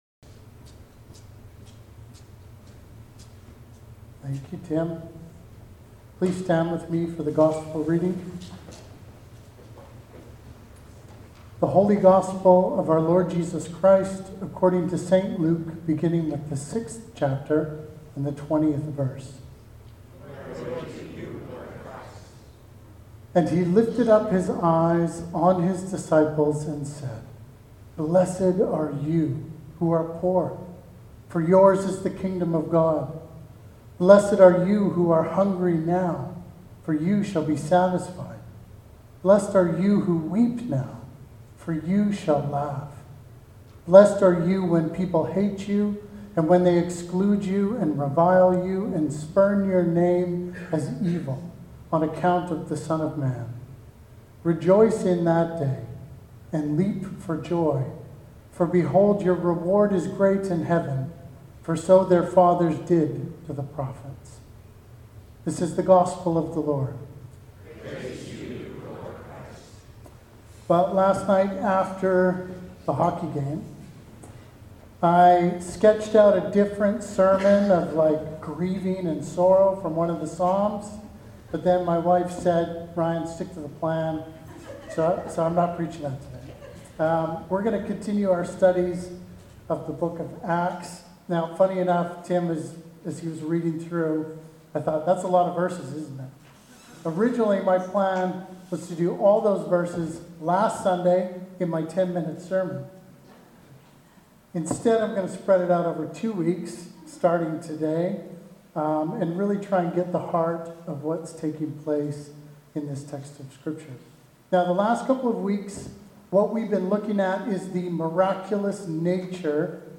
Sermons | Christ's Church Oceanside